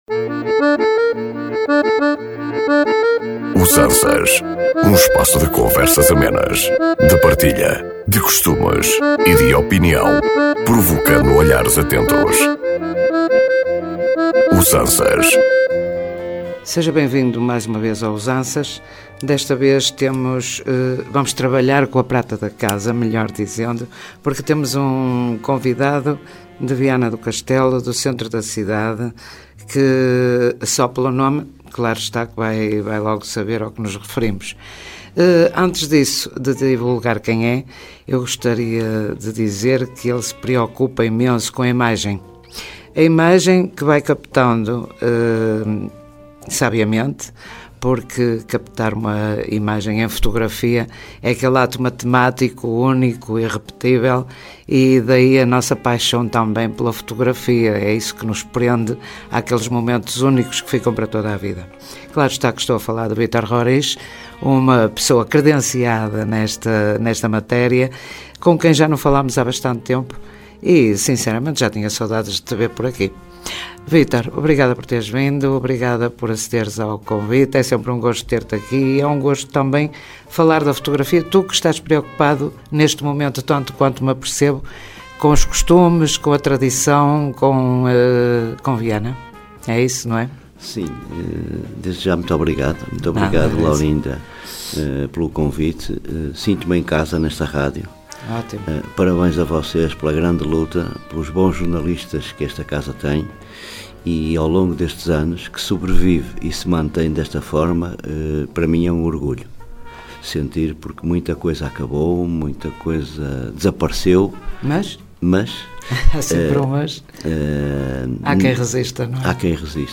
Um espaço de conversas amenas, de partilha, de costumes e de opinião, provocando olhares atentos.